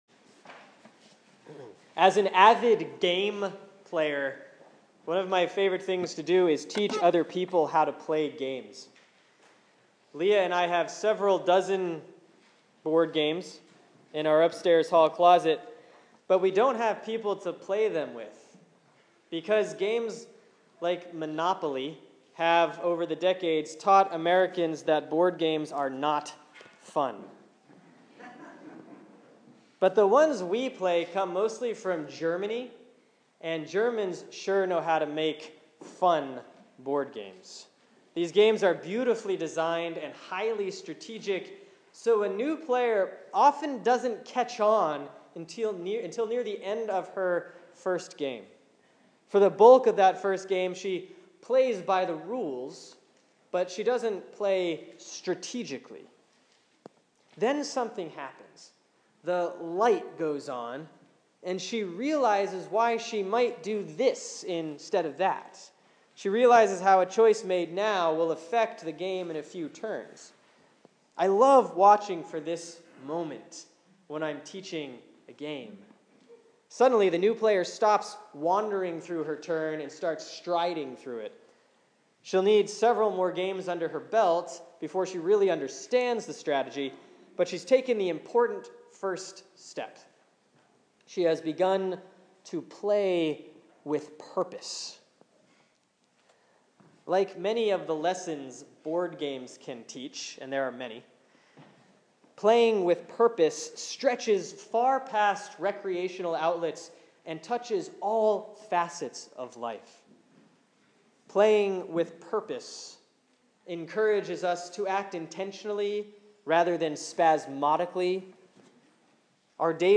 Sermon for Sunday, November 16, 2014 || Proper 28A || Matthew 25:14-30